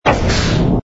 engine_ci_freighter_start.wav